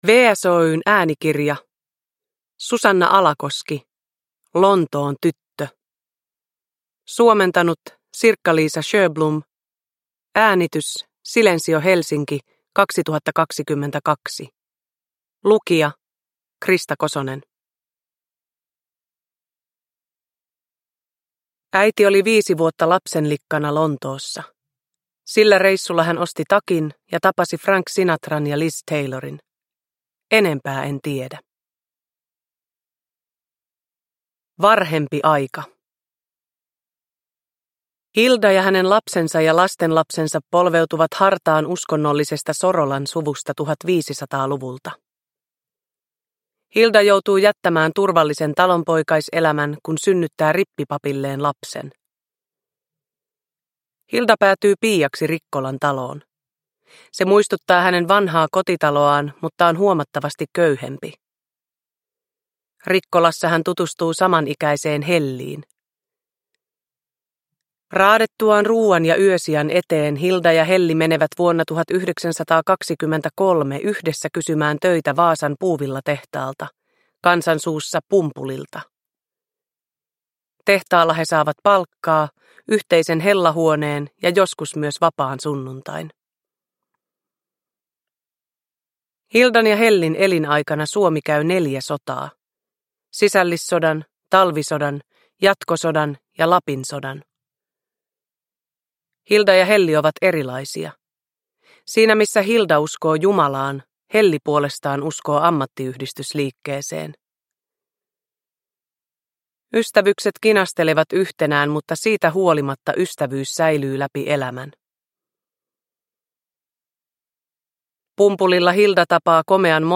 Produkttyp: Digitala böcker
Uppläsare: Krista Kosonen